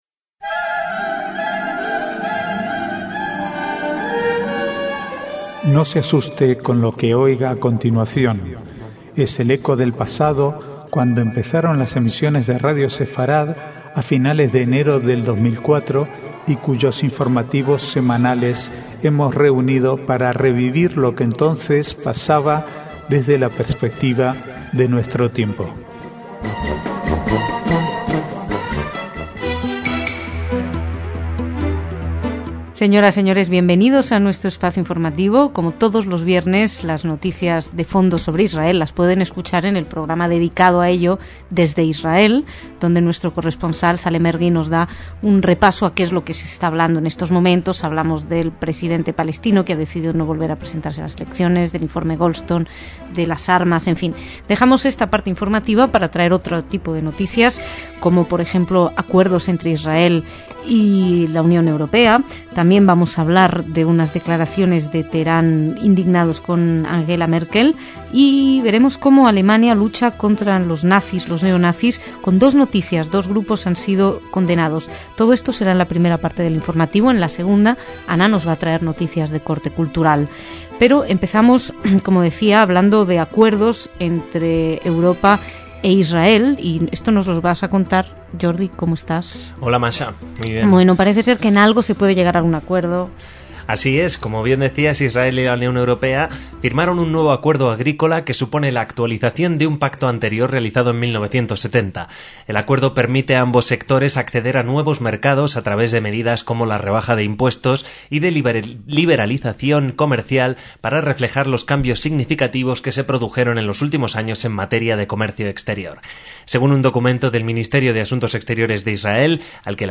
Archivo de noticias del 6 al 11/11/2009
Es el eco del pasado, cuando empezaron las emisiones de Radio Sefarad a finales de enero de 2004 y cuyos informativos semanales hemos reunido para revivir lo que entonces pasaba desde la perspectiva de nuestro tiempo.